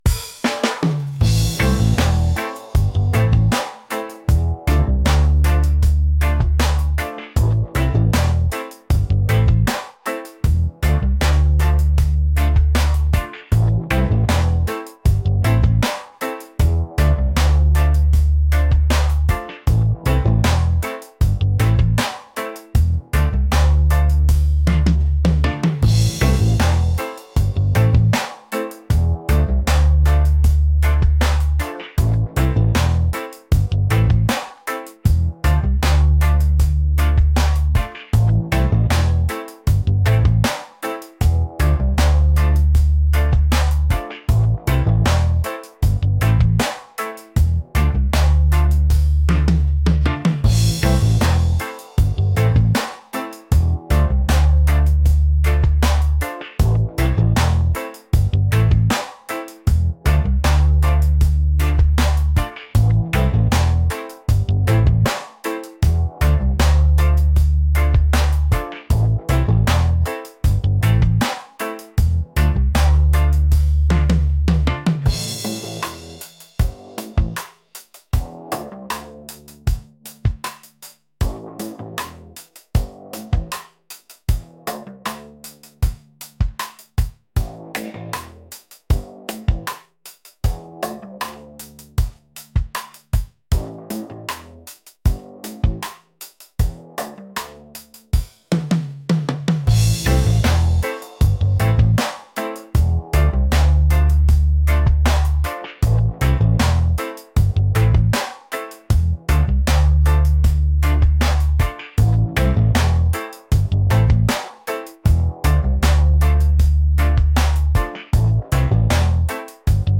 reggae | soulful | laid-back